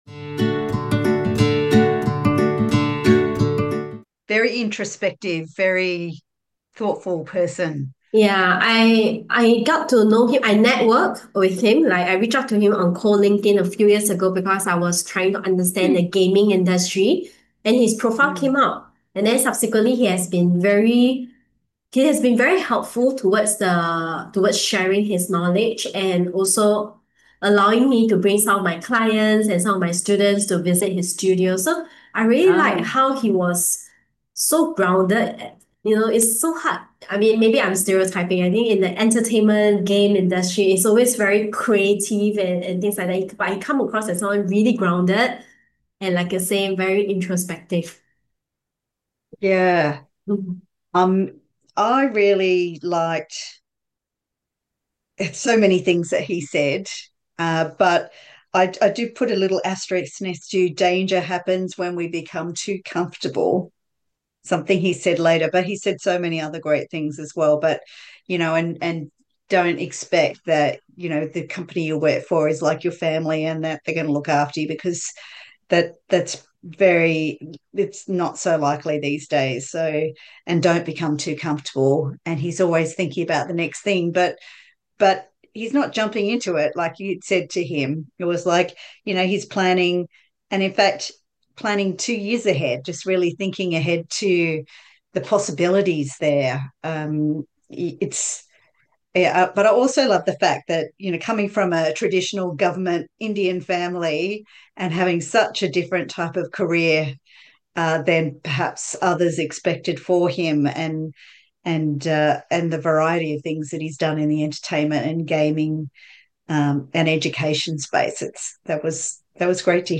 He reflects on pivotal life moments, lessons from failure, and why staying uncomfortable fuels growth. This conversation is rich with insights on creativity, entrepreneurship, and designing a career on your own terms.